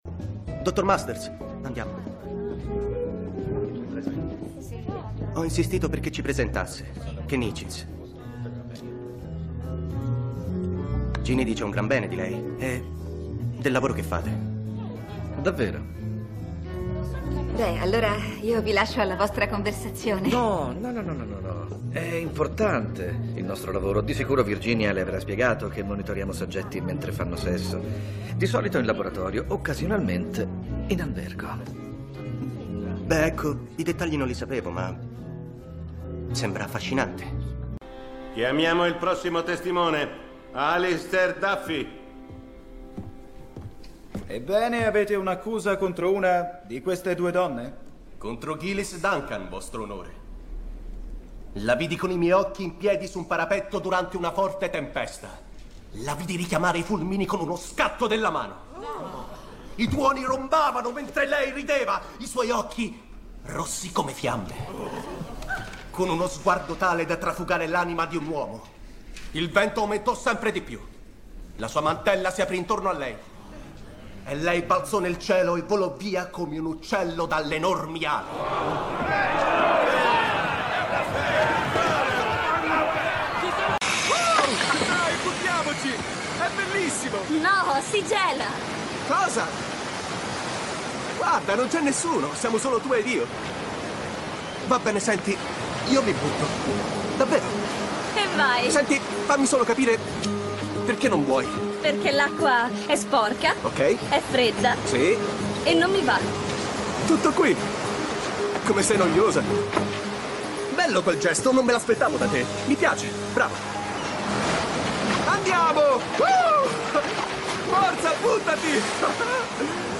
nei telefilm "Masters of Sex", in cui doppia Jeff Schine, "Outlander", in cui doppia Mark Prendergast, e "Rizzoli & Isles", in cui doppia Adam Hagenbuch.